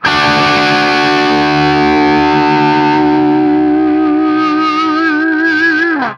TRIAD D   -R.wav